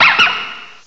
cry_not_lillipup.aif